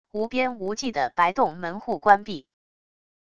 无边无际的白洞门户关闭wav音频